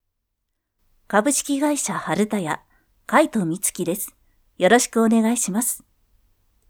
V O I C E
自己紹介